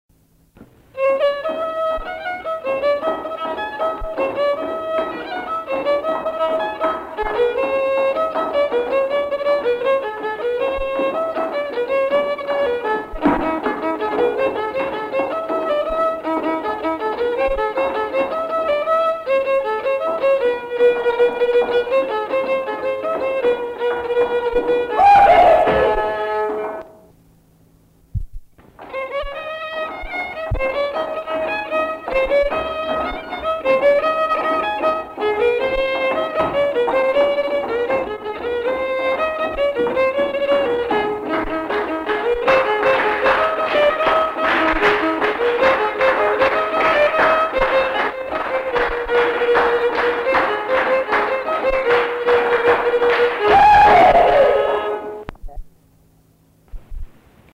Bourrées